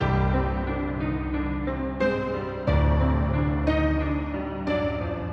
黑手党类型的陷阱钢琴
描述：在佛罗里达州的12号工作室制作
Tag: 90 bpm Trap Loops Piano Loops 918.89 KB wav Key : Unknown